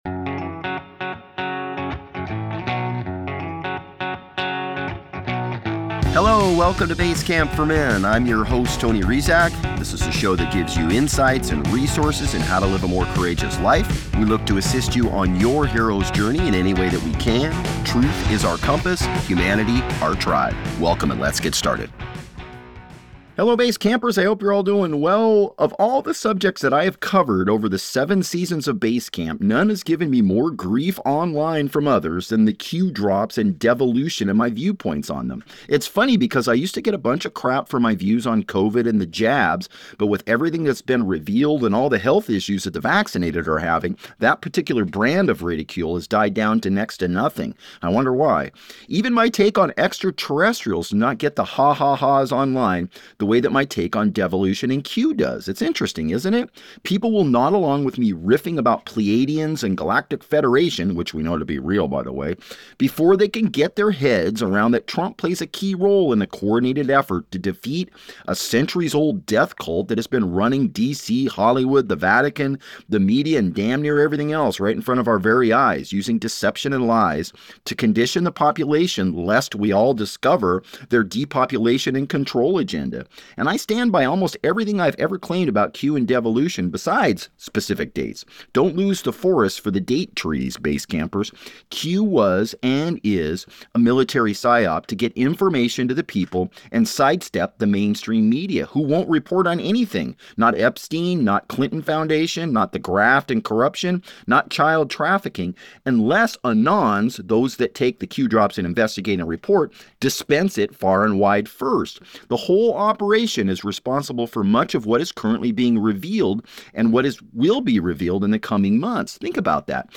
Join Basecamp for a talk